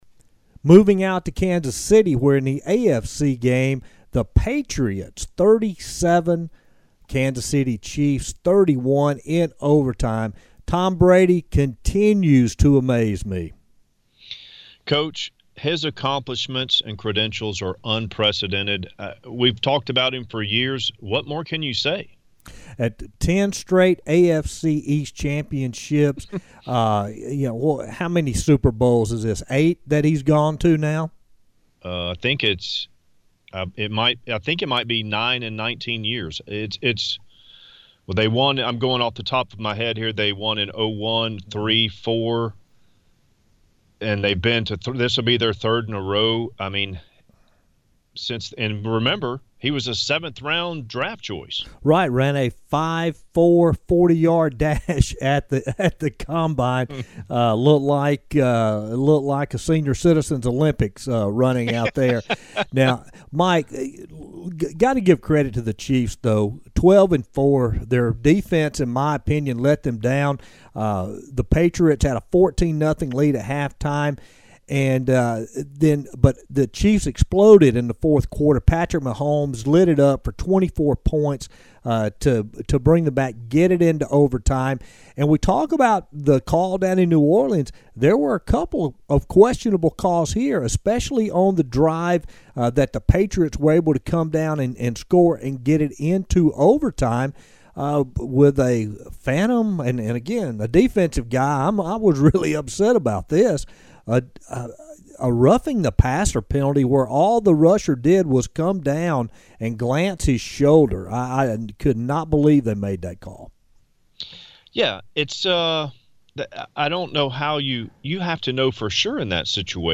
Morning Blitz guest